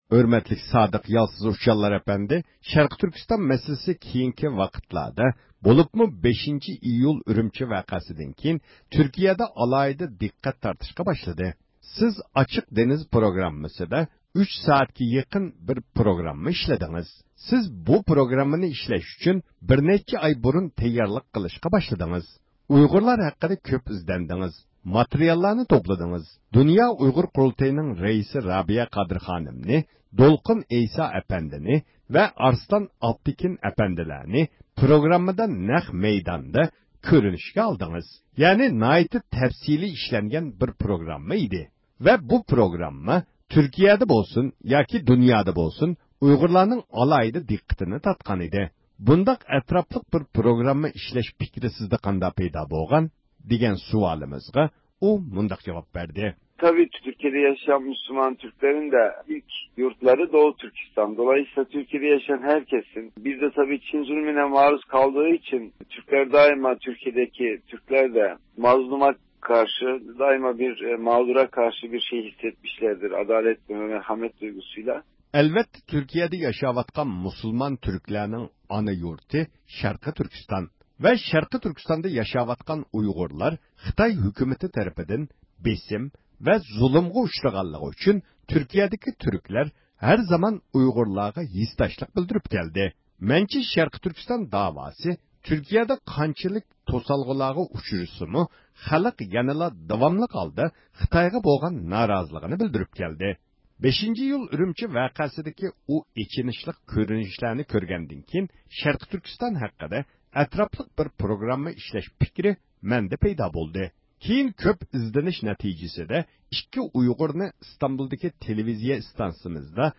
بۈگۈنكى پروگراممىمىزدا ئۇنىڭ بىلەن ئېلىپ بارغان سۆھبىتىمىزنى دىققىتىڭلارغا سۇنىمىز.